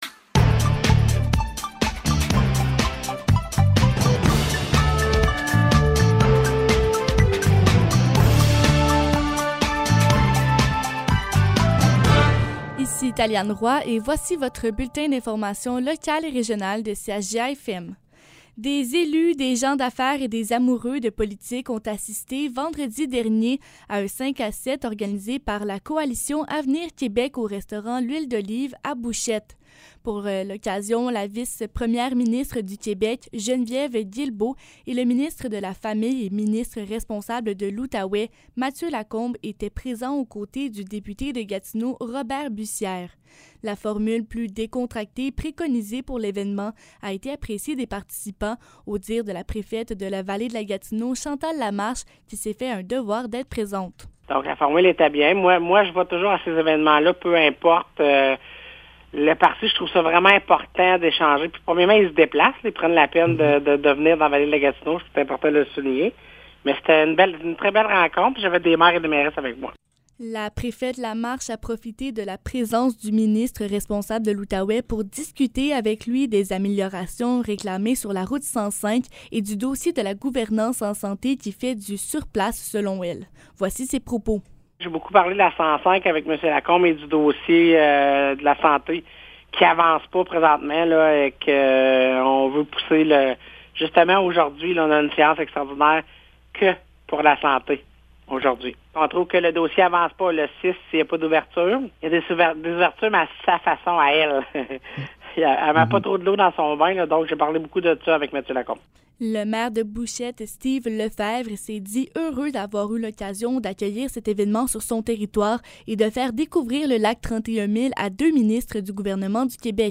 Nouvelles locales - 3 mai 2022 - 12 h